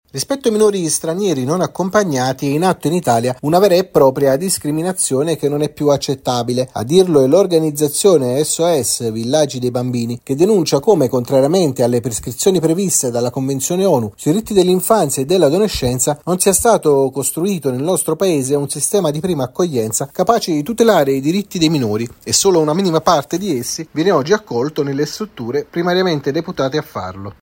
Sos Villaggio dei Bambini: in Italia discriminati i minori non accompagnati - Giornale Radio Sociale